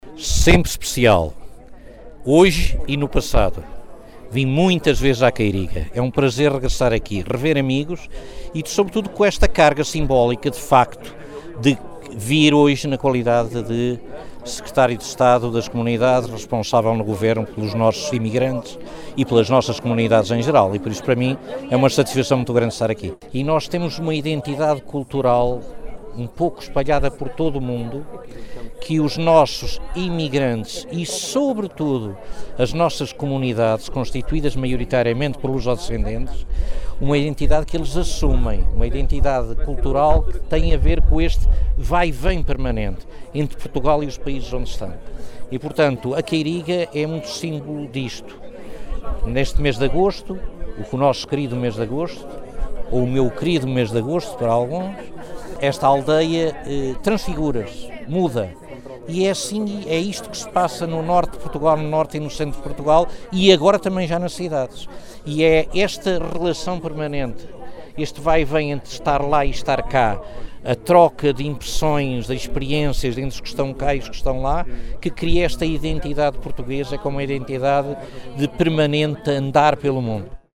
José Cesário, Secretário de Estado das Comunidades Portuguesas, em declarações à Alive Fm, diz que a sua vinda à Queiriga é sempre especial.